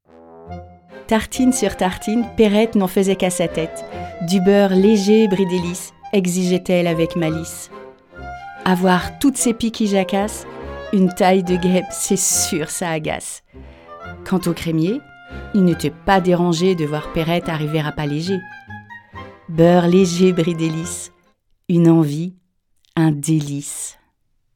Voix off
Comédienne voix off